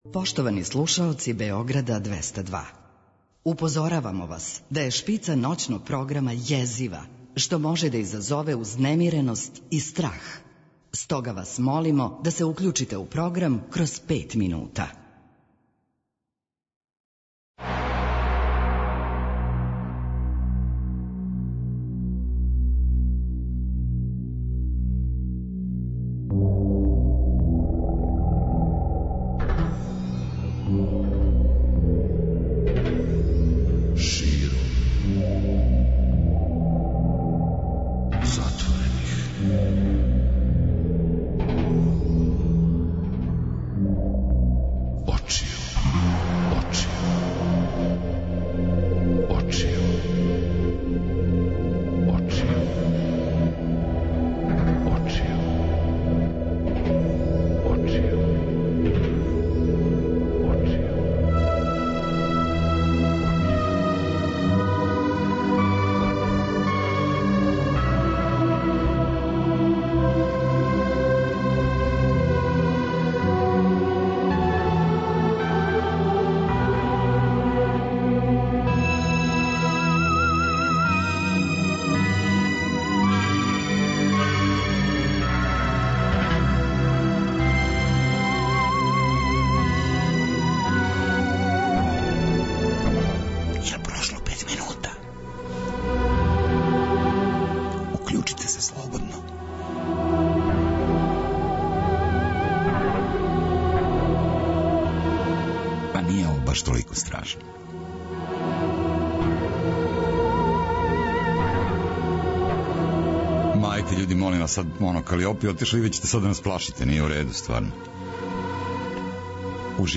преузми : 42.42 MB Широм затворених очију Autor: Београд 202 Ноћни програм Београда 202 [ детаљније ] Све епизоде серијала Београд 202 Летње кулирање Хит недеље Брза трака Домаћице и комшинице Топ листа 202